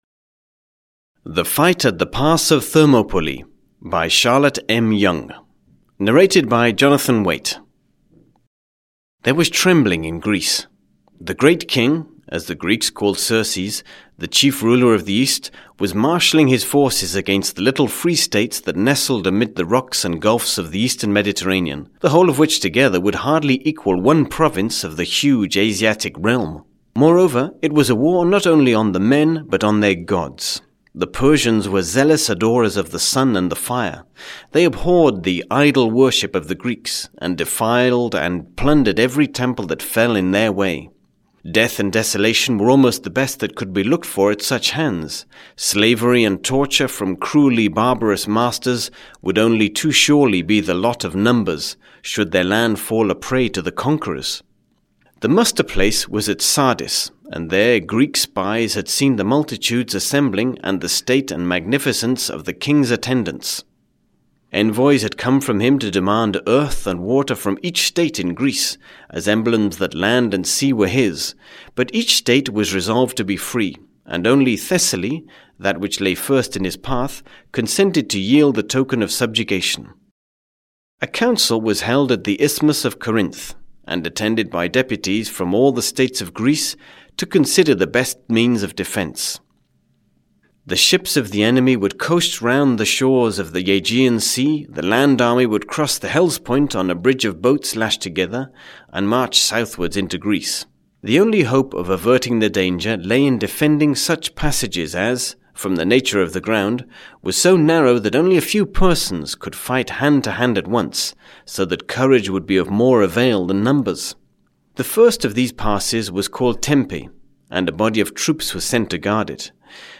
The Fight at the Pass of Thermopylae: Great Battles of History (EN) audiokniha
Ukázka z knihy